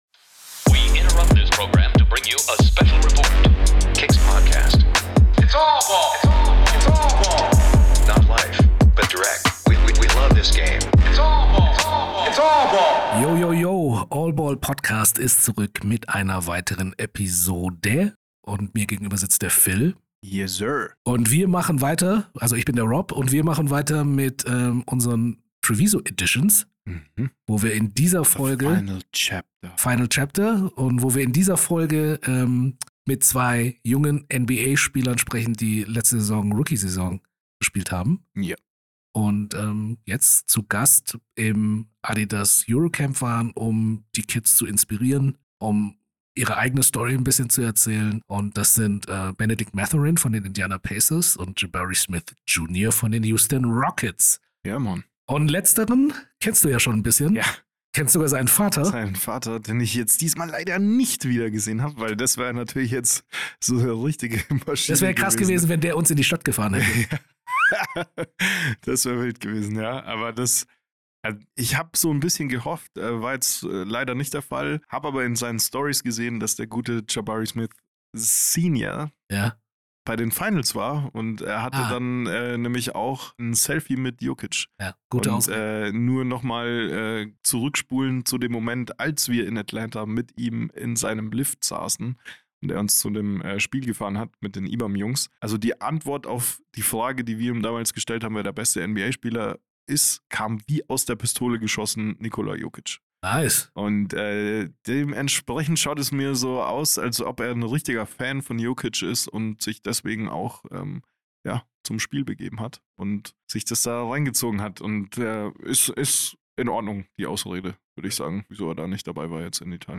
Wie versprochen, haben wir jede Menge NBA Gäste beim EuroCamp in Treviso in den Podcast holen können.